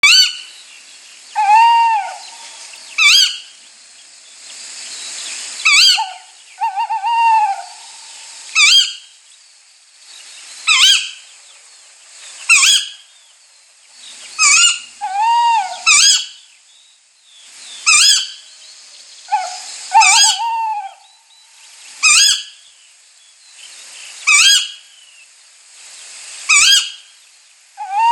Huhurezul mic (Strix aluco)
• Are ochi mari, negri, și un „strigăt” trist, ușor de recunoscut noaptea.
Ascultă cântecul de seară al huhurezului!
Huhurez-mic.m4a